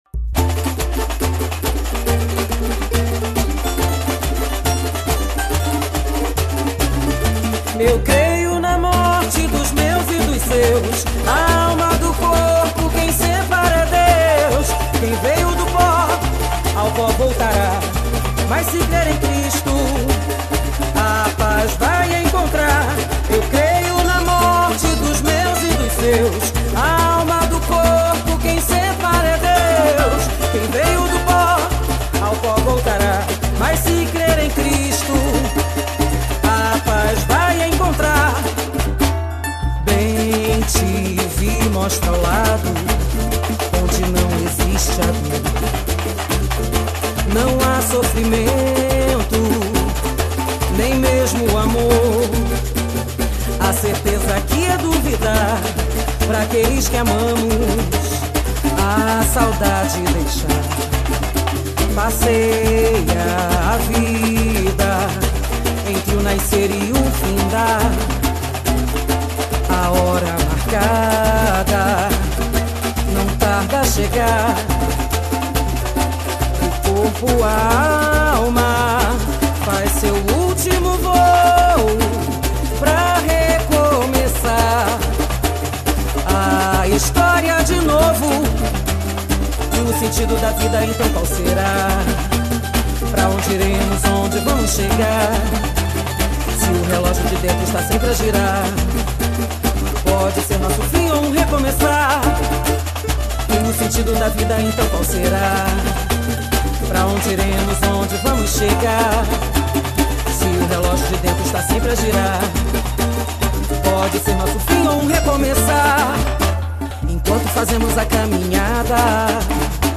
Samba  06